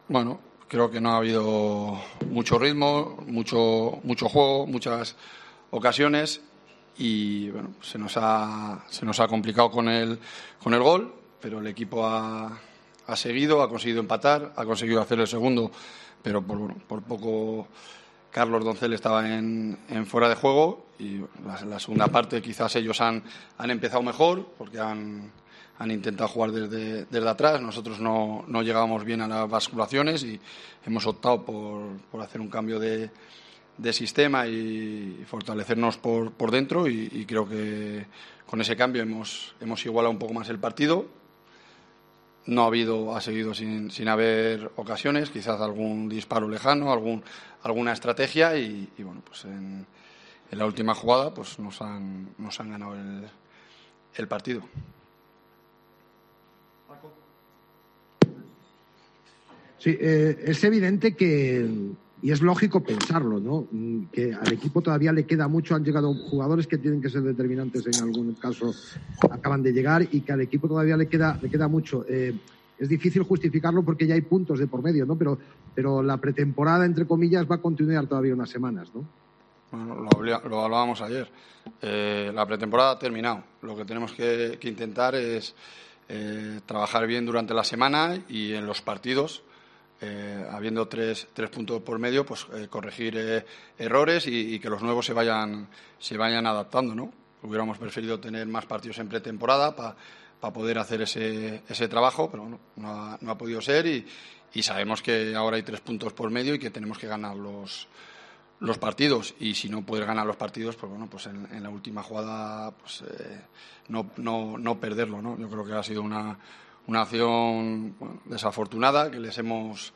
AUDIO: Escucha aquí las palabras del entrenador de la Deportiva Ponferradina tras la derrota en El Toralín 1-2 ante el Castellón